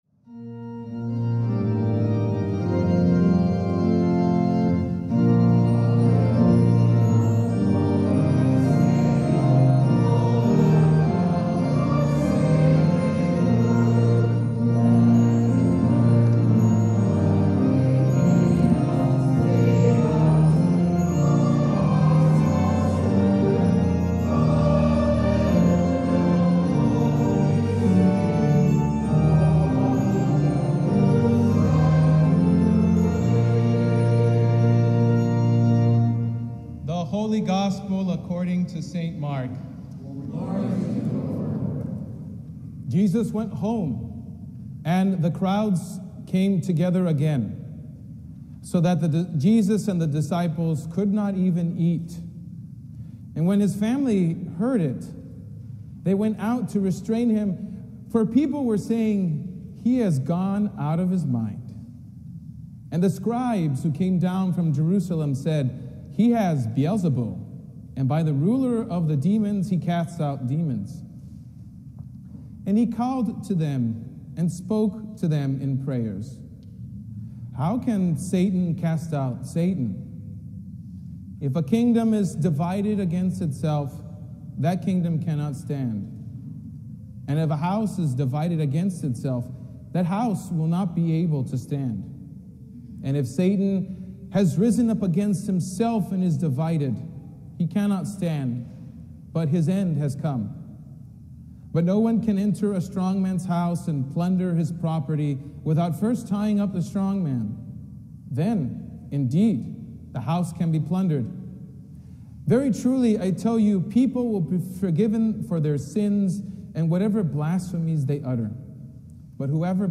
Sermon from the Third Sunday After Pentecost